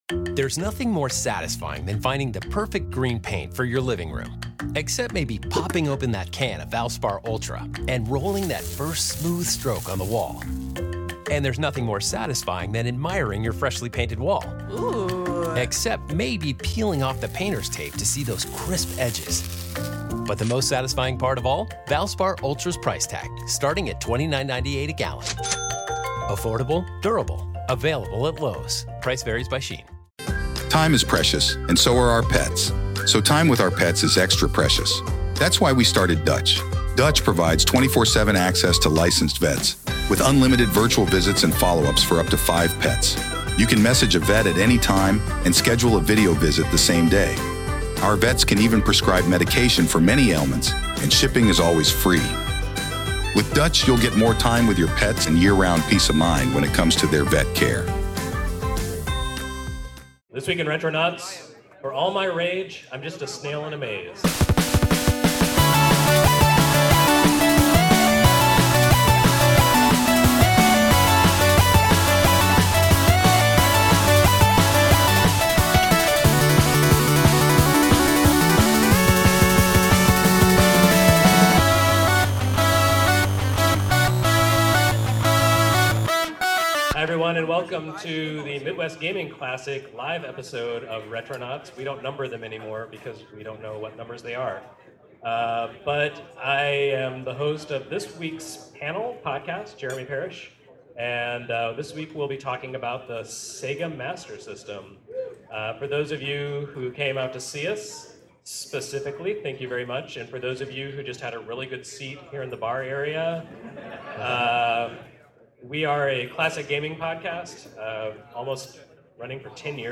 Retronauts: Live At Midwestern Gaming Classic 2016
Special thanks to 249 Studios for the live recording!